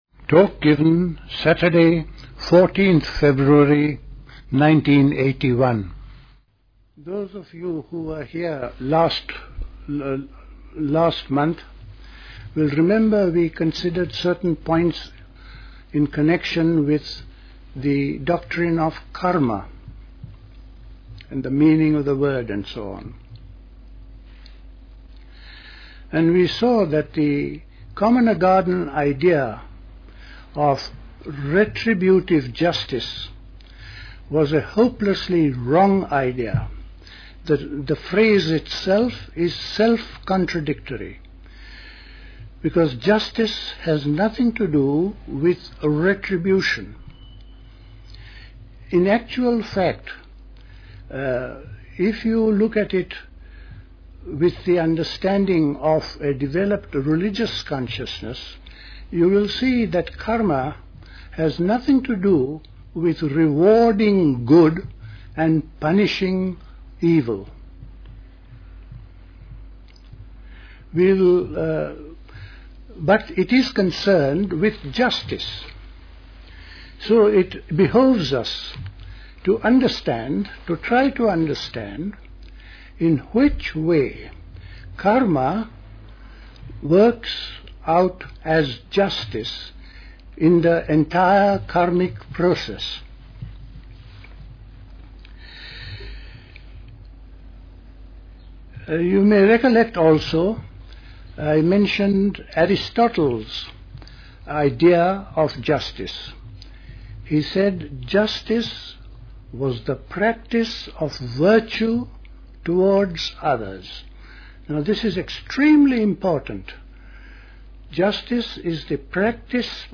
A talk
at Dilkusha, Forest Hill, London